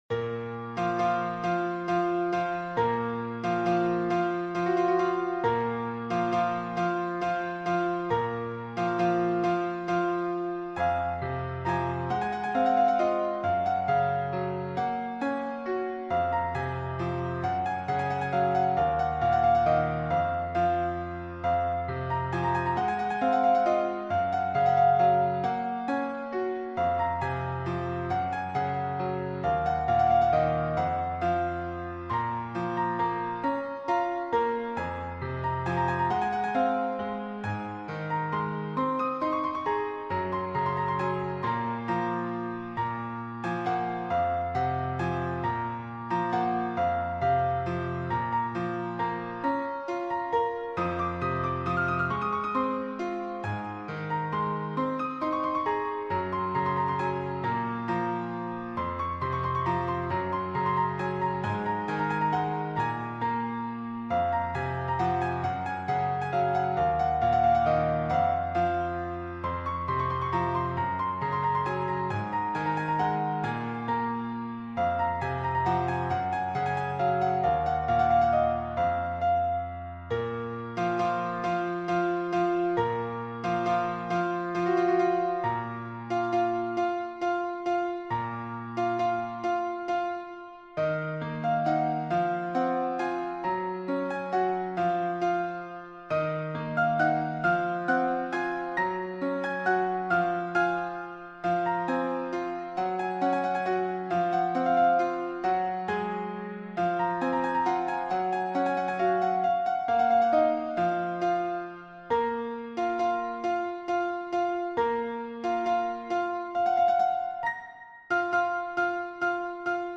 Melodiya sadə və axıcıdır, lakin çox dərin hisslər oyadır.